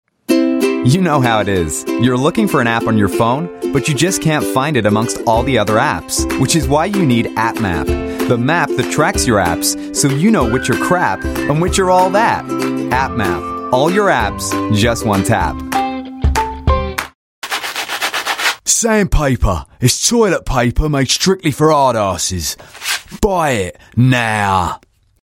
Voice Samples: Reel Sample 01
male
EN UK